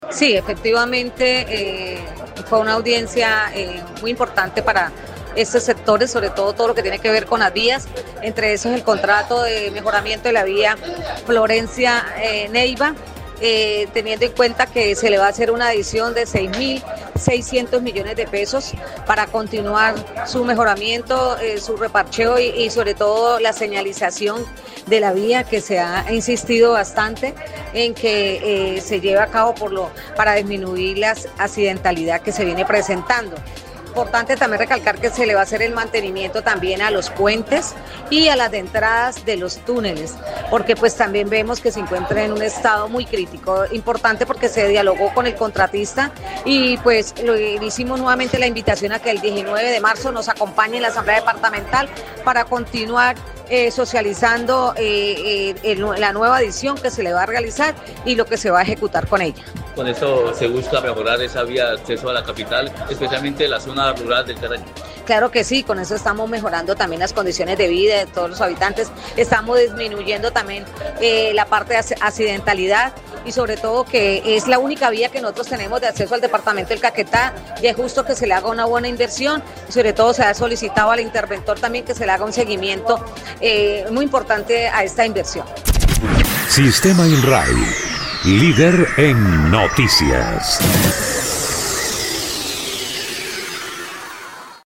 Elvia Medina Claros, diputada liberal, quien ha estado al tanto de dichas obras, explicó que lo anterior llegará para beneficio de los caqueteños, como quiera que se mejorara considerablemente la vía de acceso, disminuyendo los índices de accidentalidad.